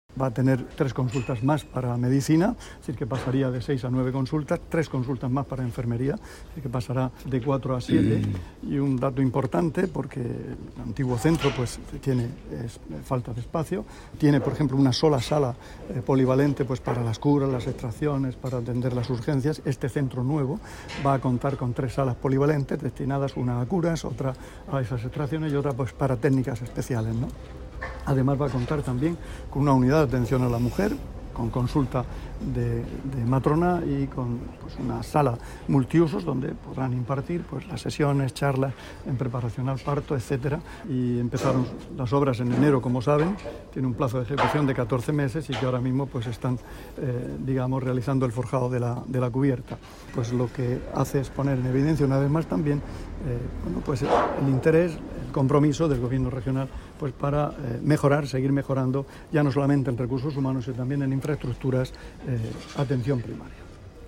Declaraciones del consejero de Salud sobre el nuevo centro de salud de Lorquí. [MP3]